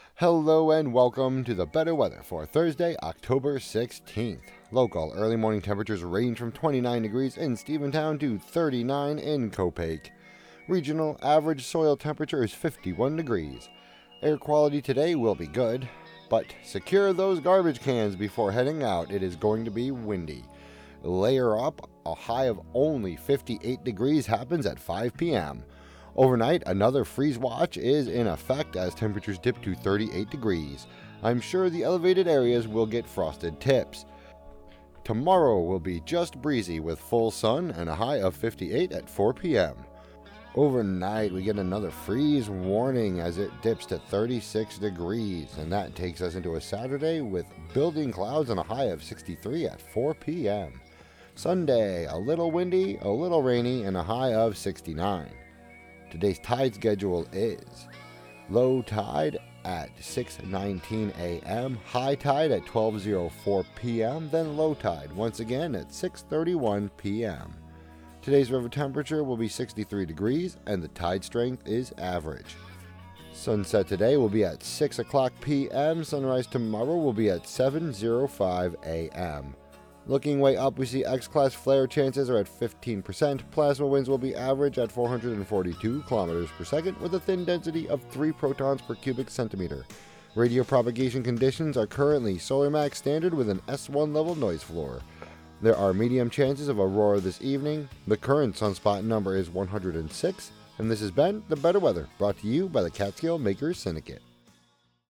and more on WGXC 90.7-FM.